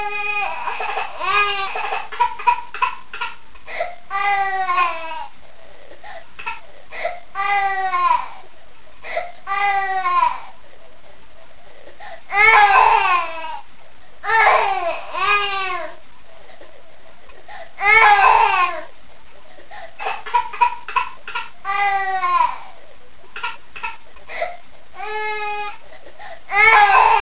Baby original Wavelet
babyMontStretch2.wav